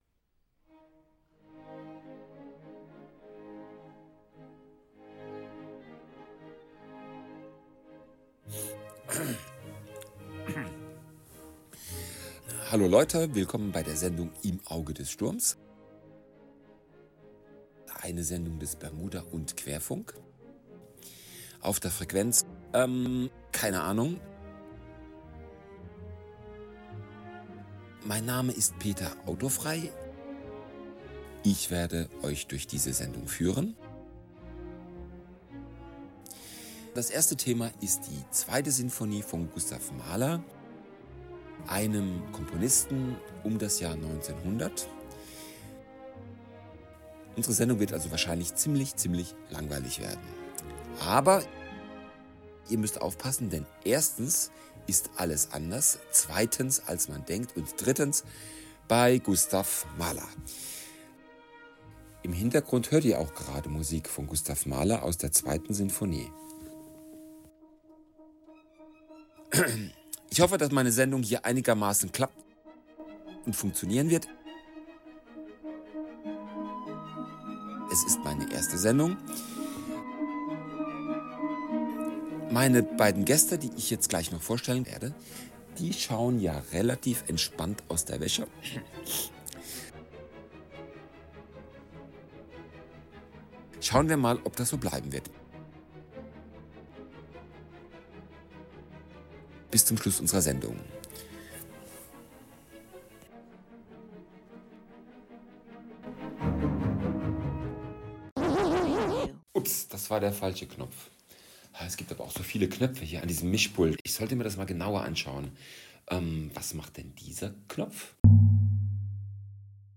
Mahler rächt sich am Publikum und an seiner Großmutter. Podcast mit Einspielungen.